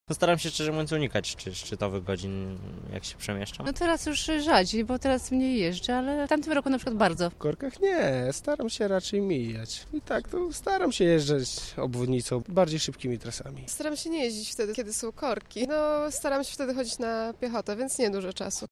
A jak mieszkańcy radzą sobie z korkami? Zapytała ich nasza reporterka.